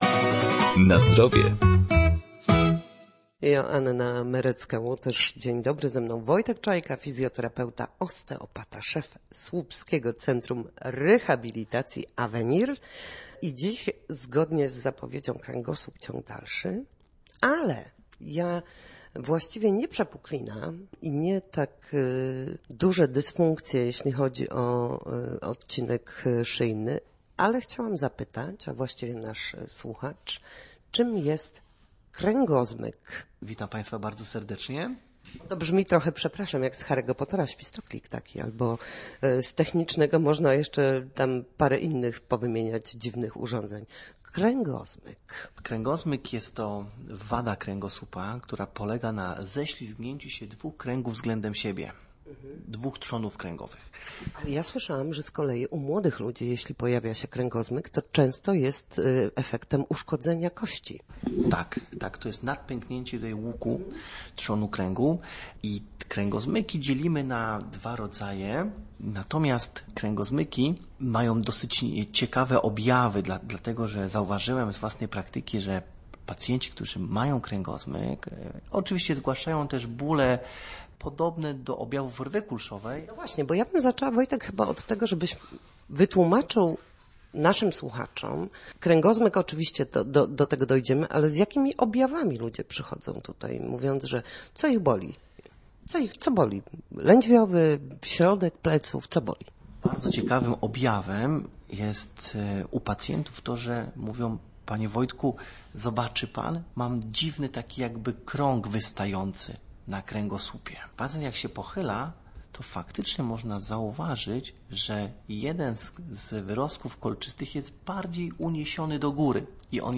We wtorek w audycji „Na zdrowie”, na antenie Studia Słupsk dyskutujemy o tym, jak wrócić do formy po chorobach i urazach.